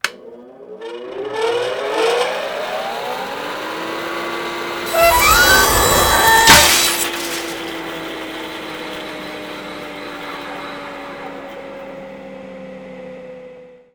cuttingtoolfail.wav